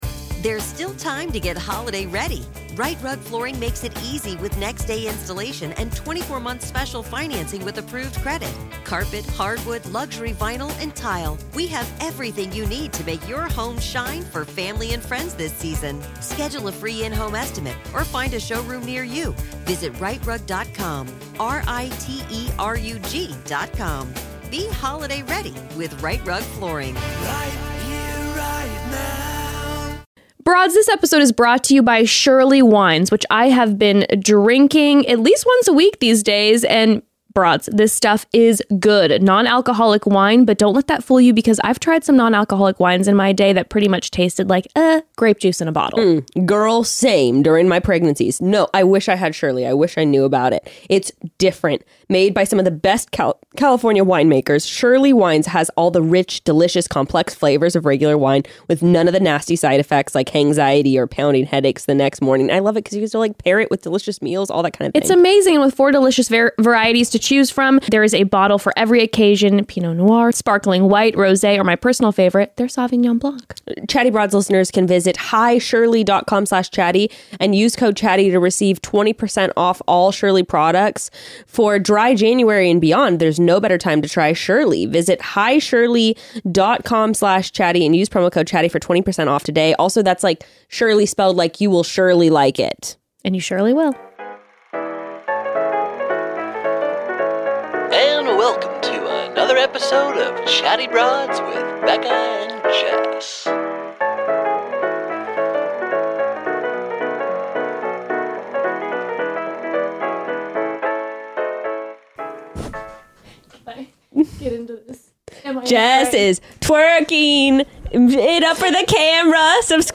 Today The Broads excitedly chat and laugh about singing karaoke too well